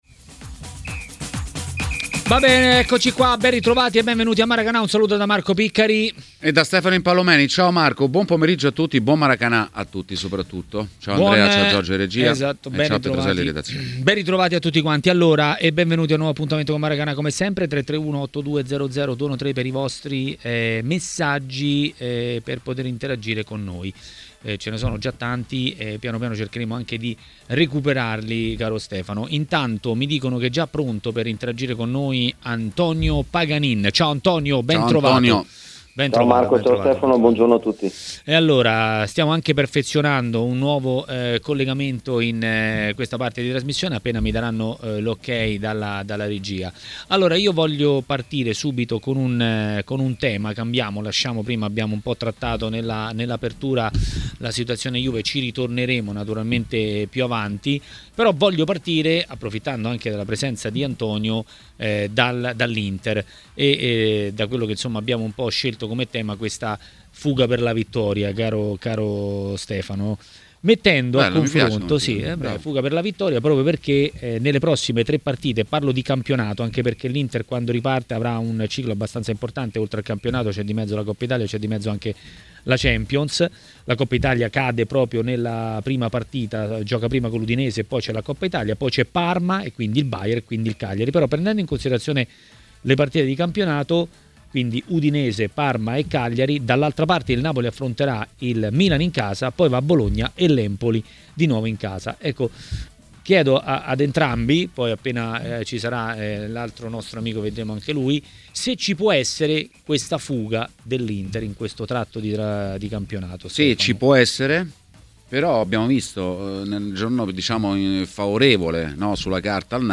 A parlare di Inter a TMW Radio, durante Maracanà, è stato l'ex calciatore Antonio Paganin.